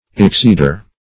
Exceeder \Ex*ceed"er\, n. One who exceeds.